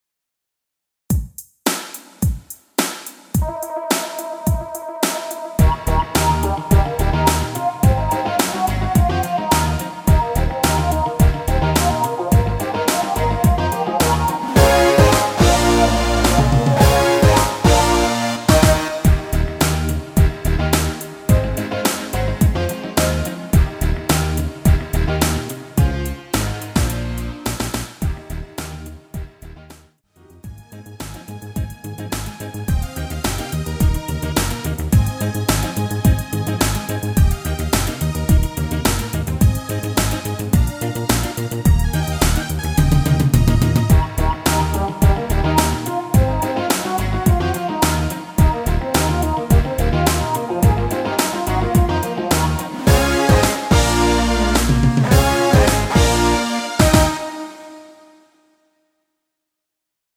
편곡 MR입니다.
원키에서(-1)내린 마지막 반복 되는 오오오~ 없이 엔딩을 만들었습니다.(미리듣기및 가사 참조)
Eb
앞부분30초, 뒷부분30초씩 편집해서 올려 드리고 있습니다.